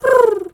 pigeon_2_call_01.wav